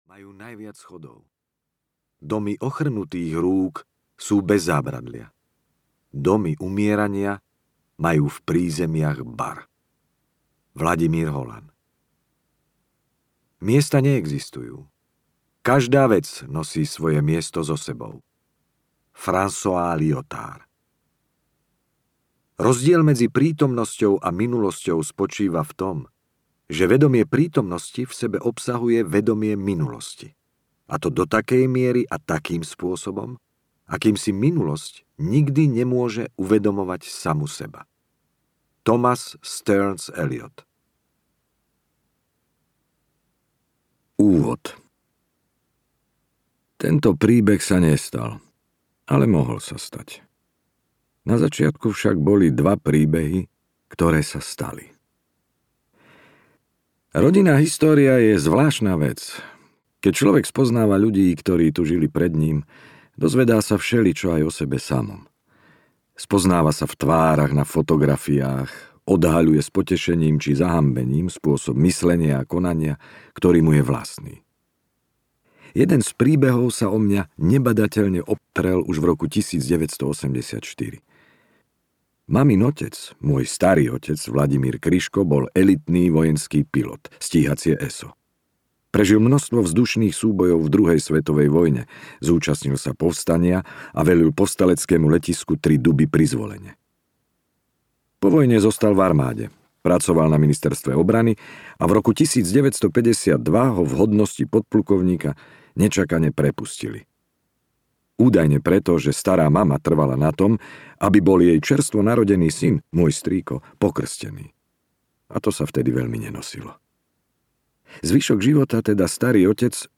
Dom hluchého audiokniha
Ukázka z knihy